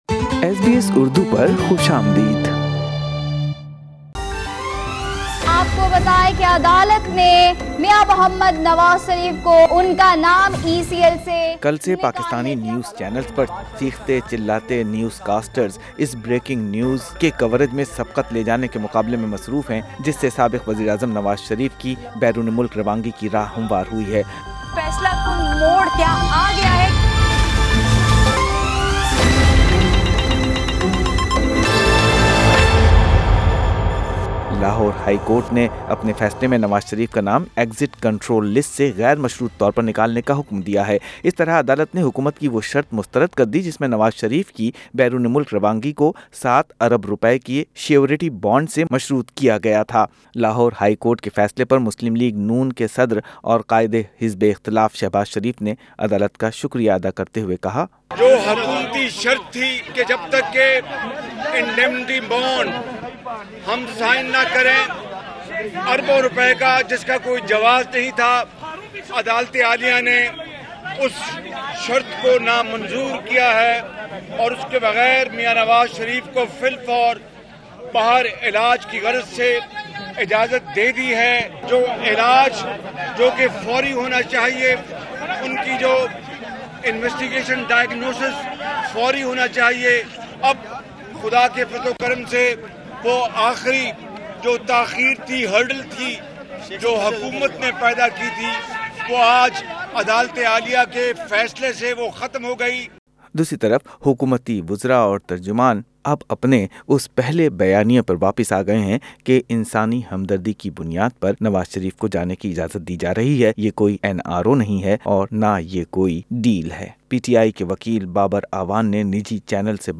Source: Getty نواز شریف کے حوالے سے لاہور ہائی کورٹ کے فیصلے سے متعلق شہباز شریف، بابر اعوان، فواد چوہدری اور فردوس عاشق اعوان کی رائے سنئیے اس پوڈکاسٹ میں شئیر